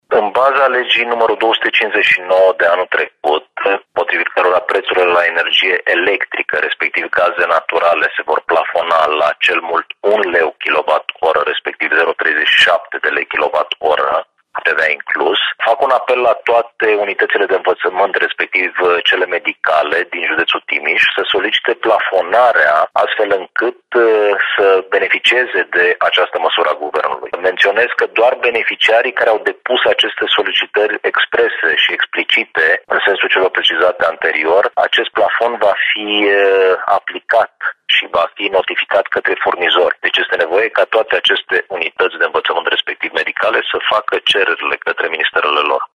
Subprefectul Ovidiu Drăgănescu: